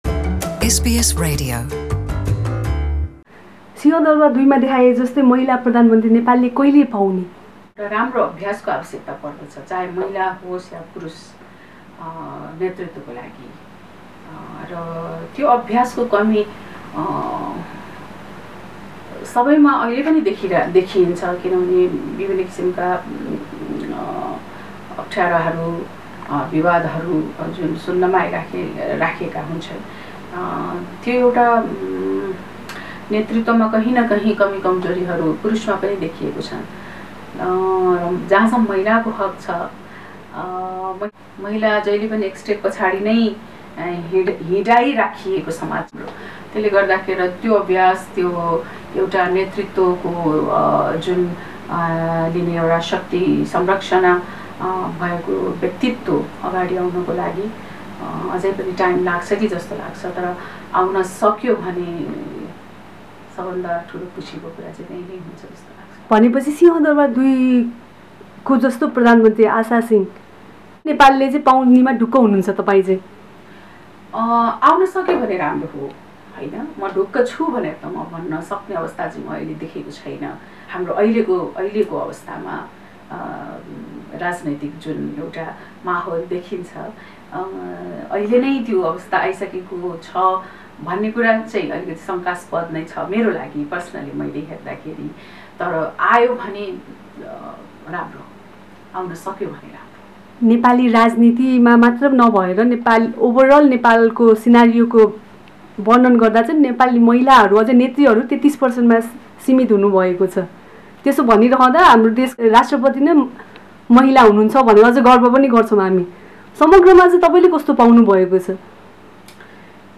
Not until there is a change in the way we view women says actor Gauri Malla, who is currently doing the role of Nepal's first prime minister for a television series titled "Singha Durbar 2". Malla spoke to SBS Nepali about the role and what it will take for a Nepali woman to lead the country.